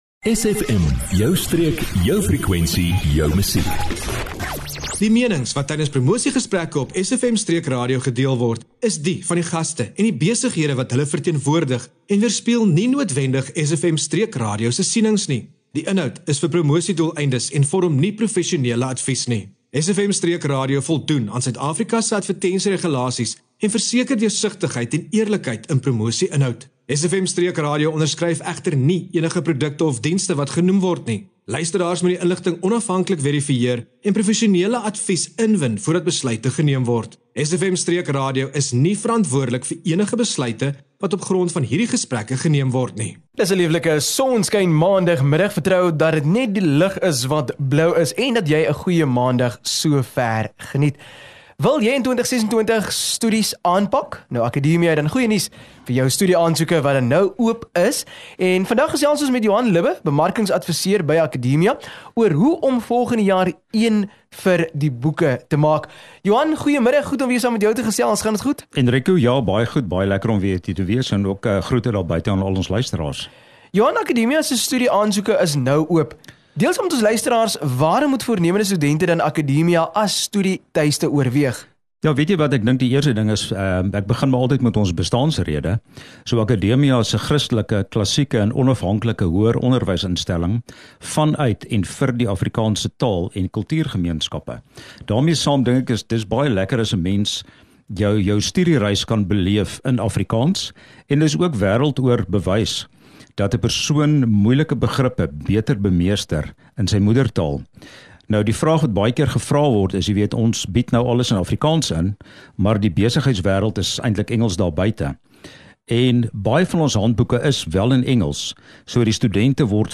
8 Apr Akademia onderhoud 07 April 2025